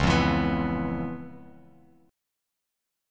Db7#9 chord